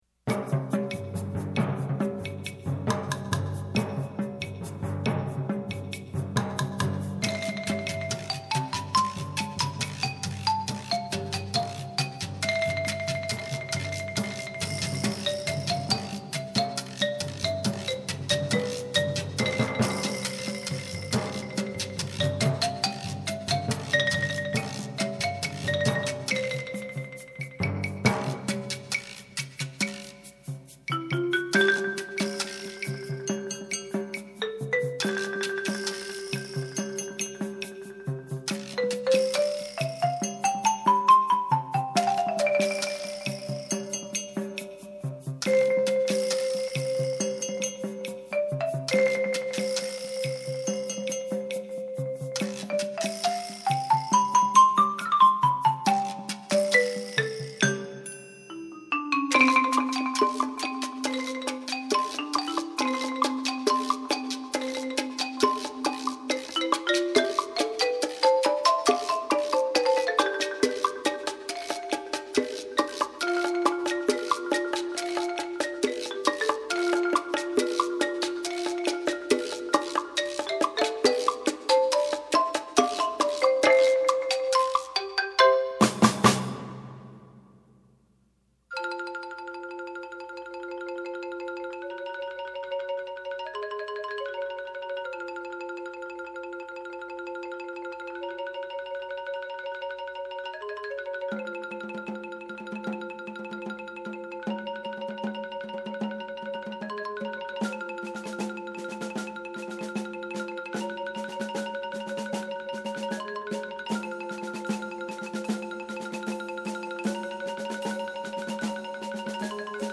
Voicing: 6-8 Percussion